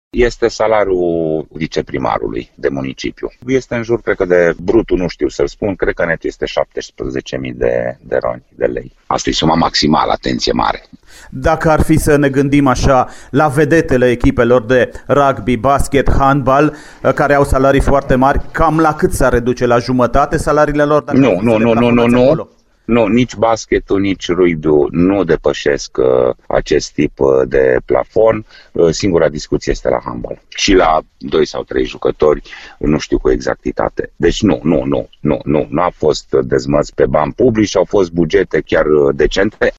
într-un interviu la Radio Timișoara